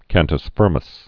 (kăntəs fîrməs, fûr-)